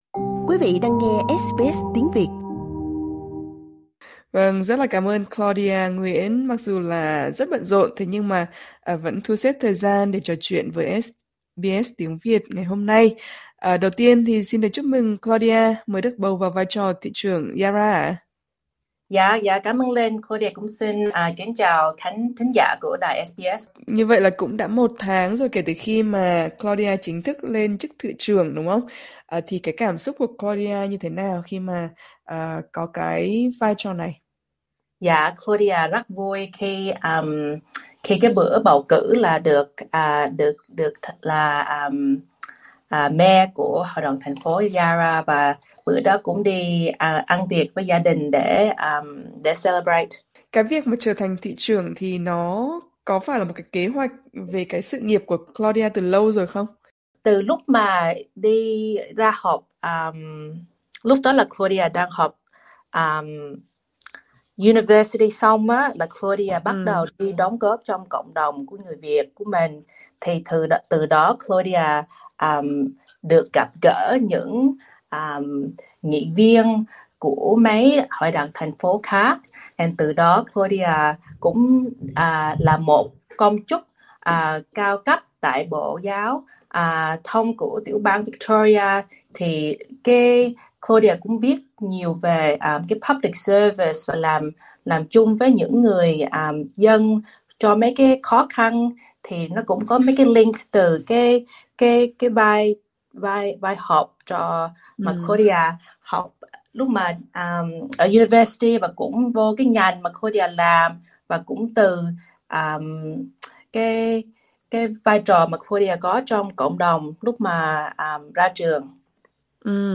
Trò chuyện với SBS Tiếng Việt, Claudia chia sẻ niềm đam mê đóng góp cho cộng đồng, những thách thức hiện tại ở vùng Yarra, và những kế hoạch sắp tới cho khu vực.